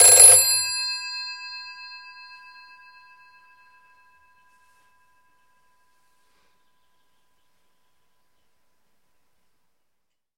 Phone » BakelitePhoneRing
描述：An old bakelite phone ringing. It's an Ericsson RIJEN made in 1965.
标签： environmentalsoundsresearch ring old phone
声道立体声